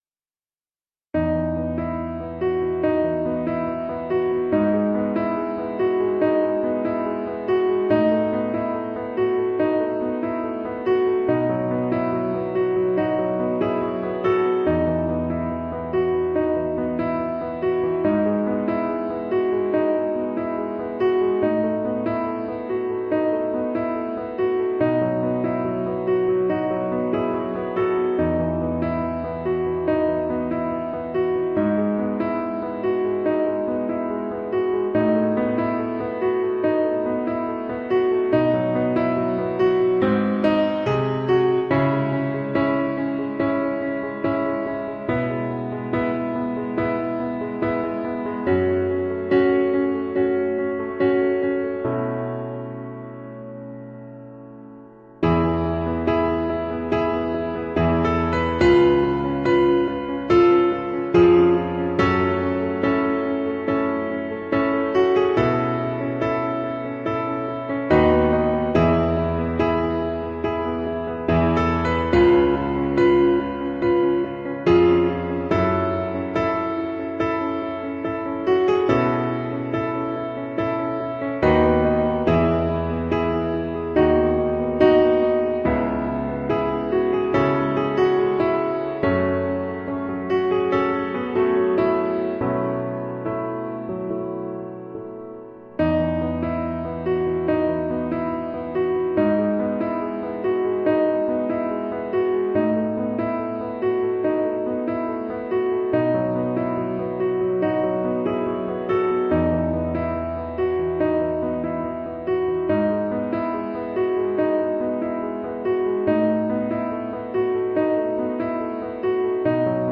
The sadness of the words is most haunting and in the music too.